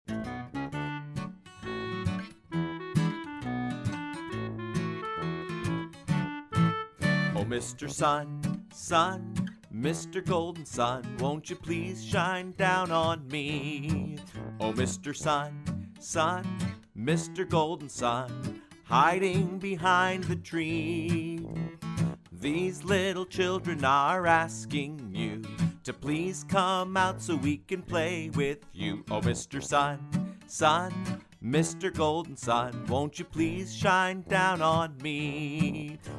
Vocal Song Downloads
Demo MP3